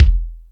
Lotsa Kicks(42).wav